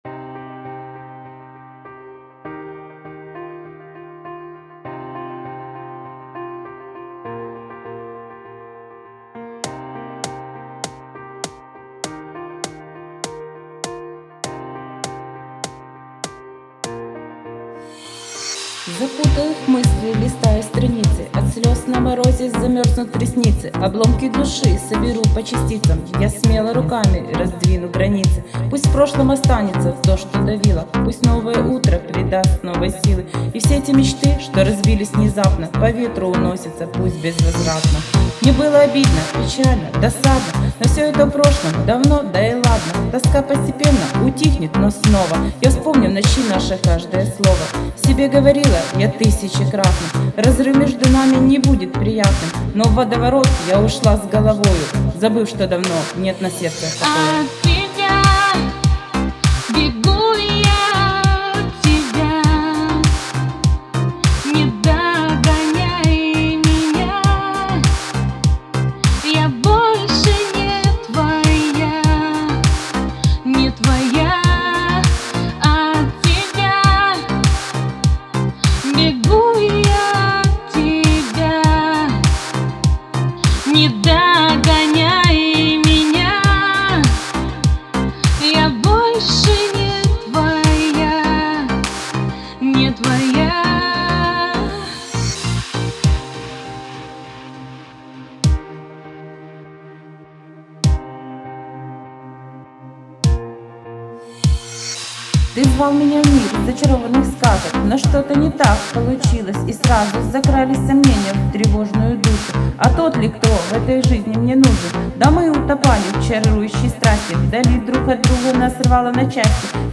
Дуэт..
сделано в интернете сквозь тысячи км друг от друга..